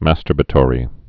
(măstər-bə-tôrē)